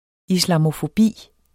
Udtale [ islɑmofoˈbiˀ ]